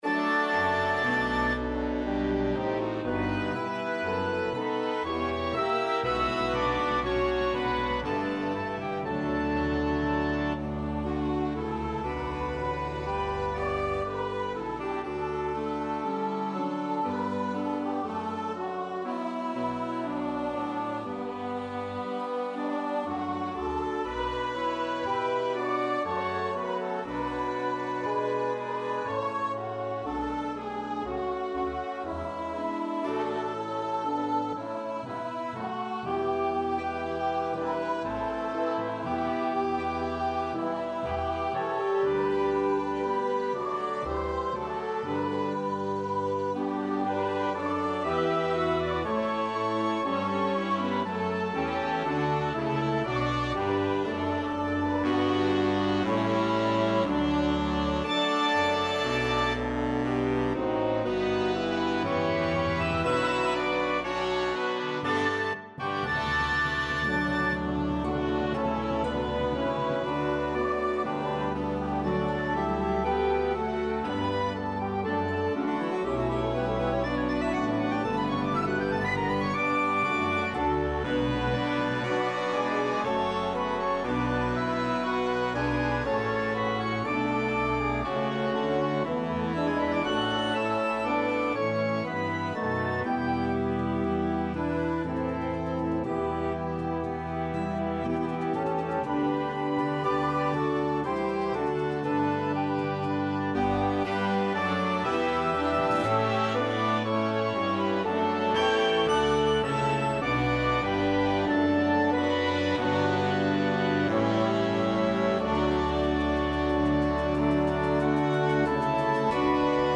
arranged for wind band
Wind band